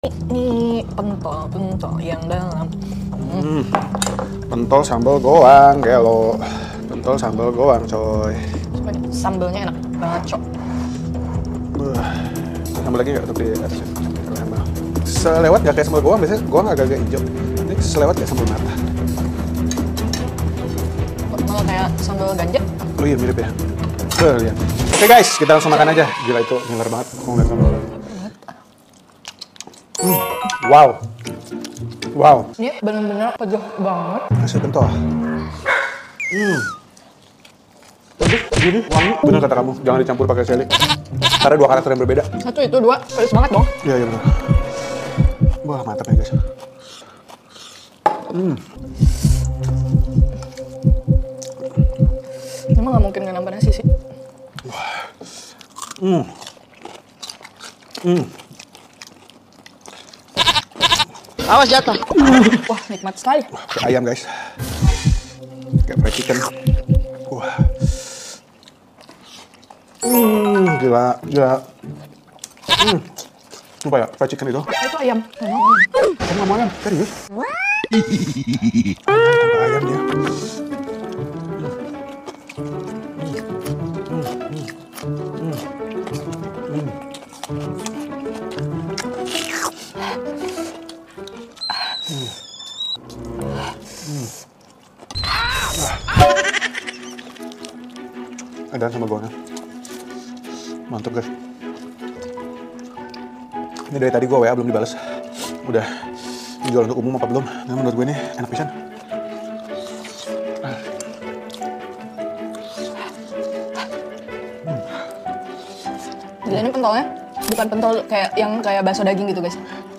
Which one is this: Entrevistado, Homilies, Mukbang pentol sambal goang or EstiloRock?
Mukbang pentol sambal goang